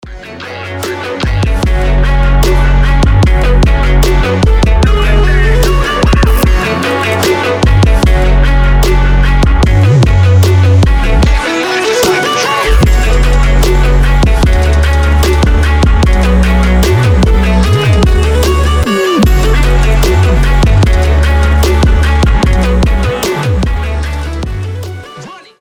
• Качество: 320, Stereo
громкие
скрипка
качающие
Крутой трап саунд